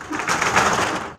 door_metal_gate_move1.wav